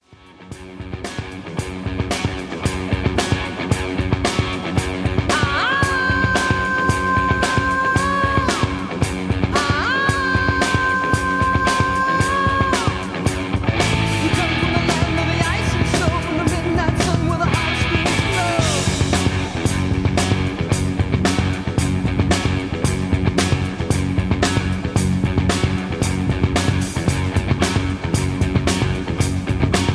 Tags: rock